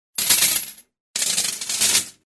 Звуки цепи
Цепь лежит на металлической поверхности